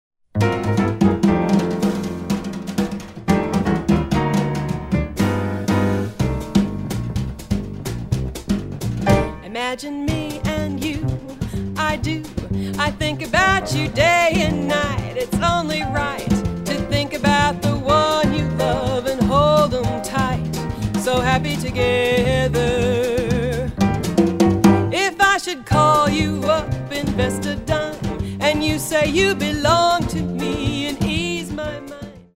A vibrant vocalist with a love of both jazz and modern music
rich and expressive vocals
jazz vocalist